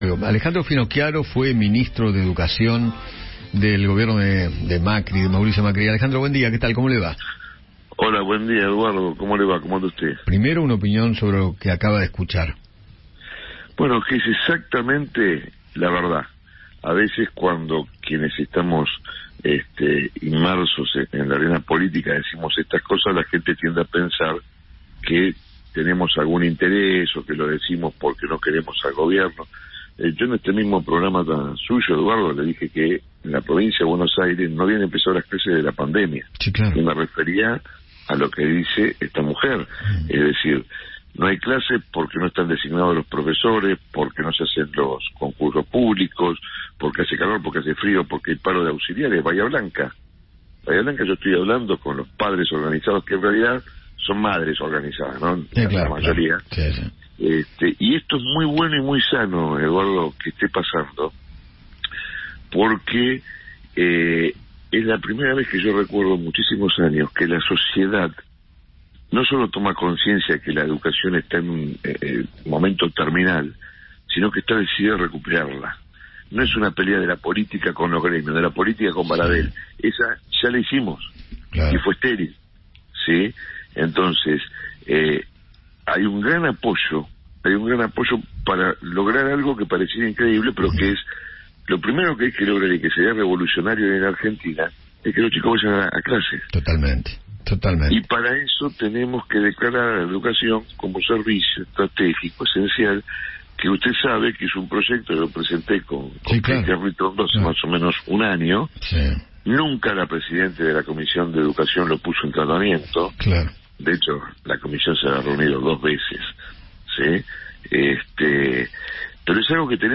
Alejandro Finocchiaro, ministro de Educación durante la presidencia de Mauricio Macri, habló con Eduardo Feinmann sobre el nivel educativo del país y se refirió al aumento de las Becas Progresar.